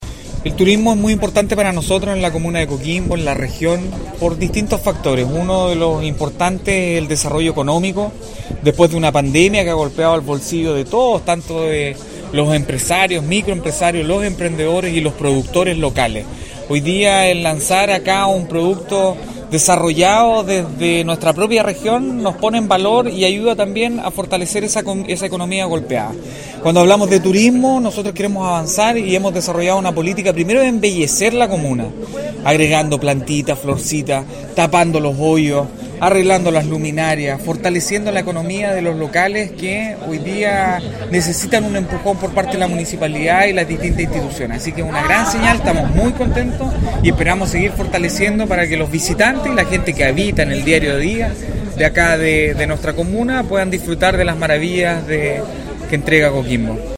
AUDIO : Ali Manouchehri Alcalde Coquimbo